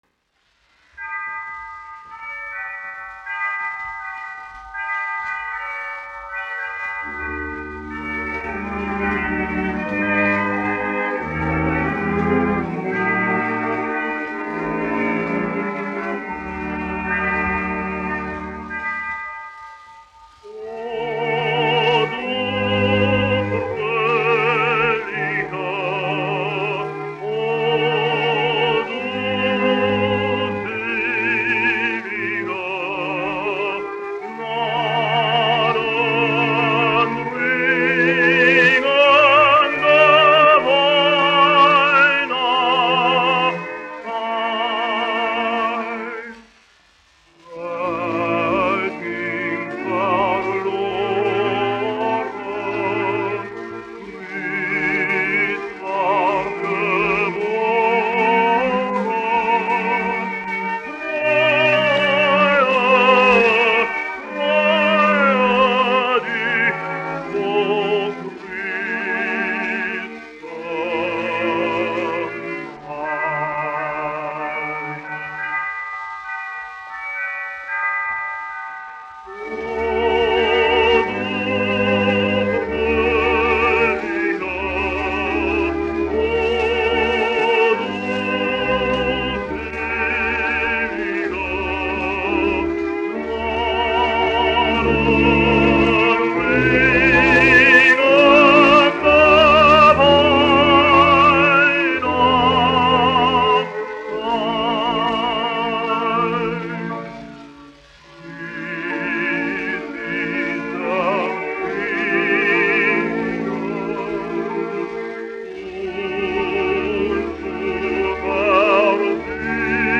1 skpl. : analogs, 78 apgr/min, mono ; 25 cm
Ziemassvētku dziesmas
Latvijas vēsturiskie šellaka skaņuplašu ieraksti (Kolekcija)